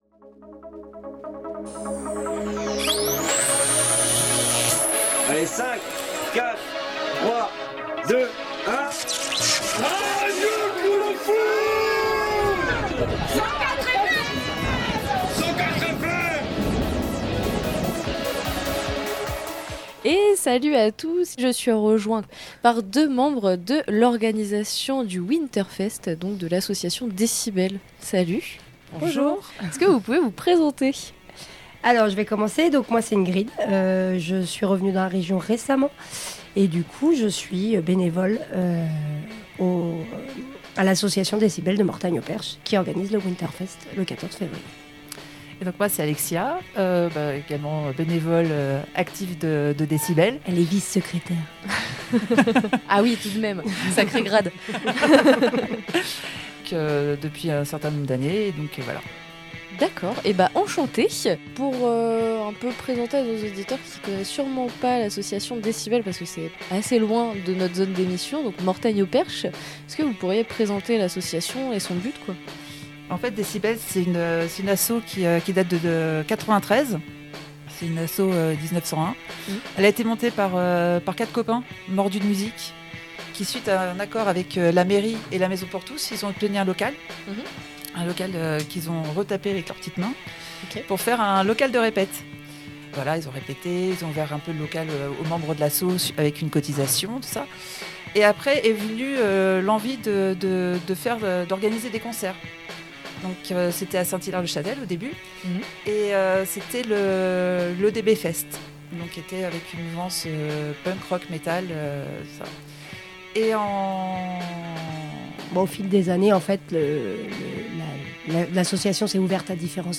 Interviews RCDF Le DB Winter Fest !